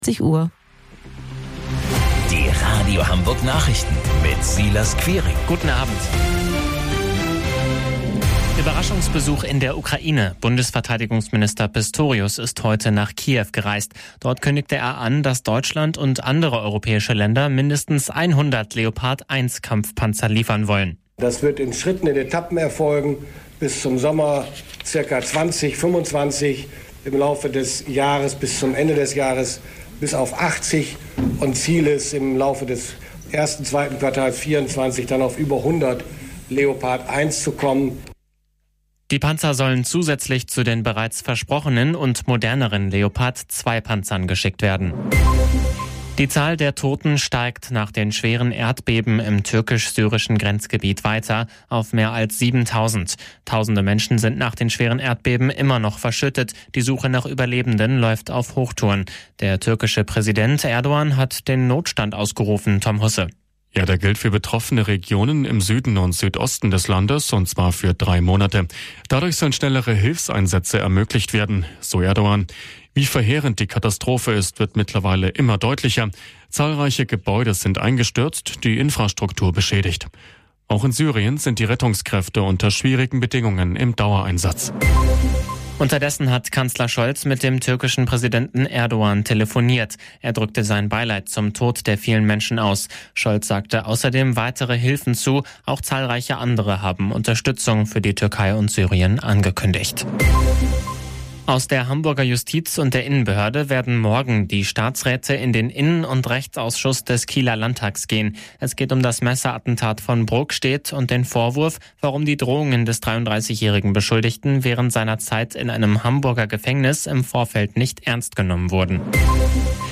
Radio Hamburg Nachrichten vom 16.09.2022 um 23 Uhr - 16.09.2022